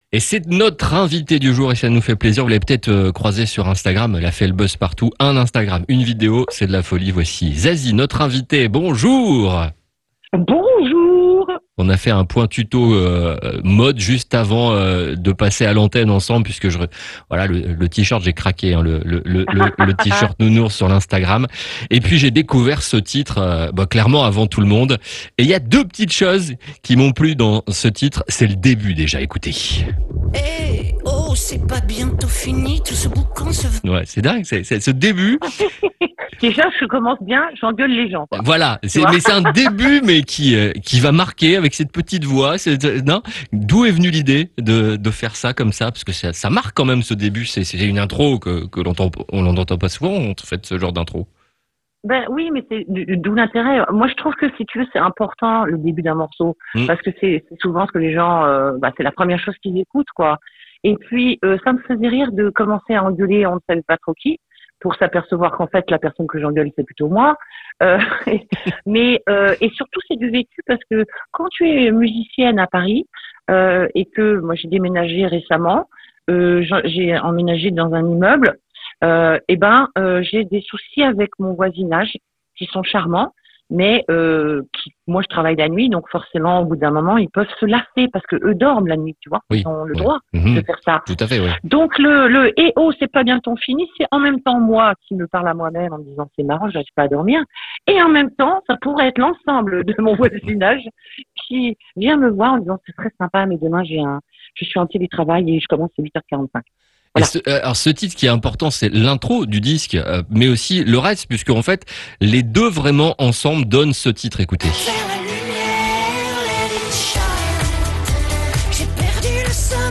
Zazie était notre invité, vendredi 01 juillet à 11h45 ! Elle est venue nous parler de son nouveau titre “Let it shine” !